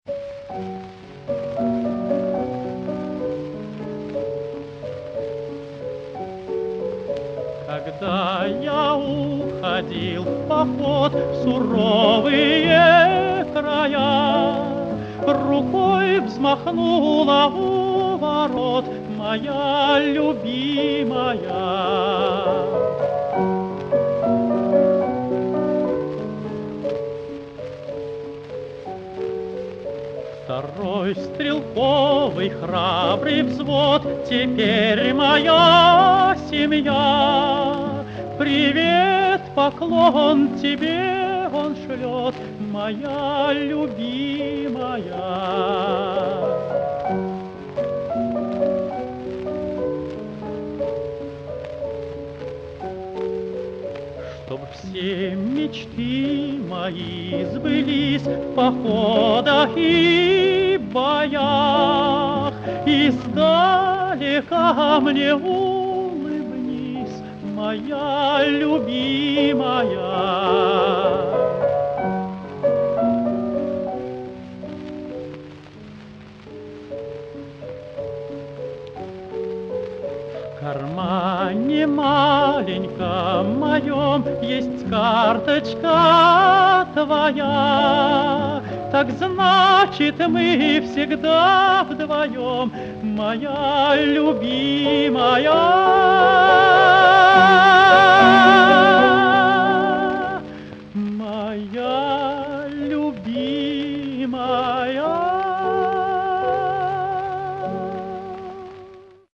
Одна из лучших военных песен о любви в прекраснейшем
С чешской пластинки.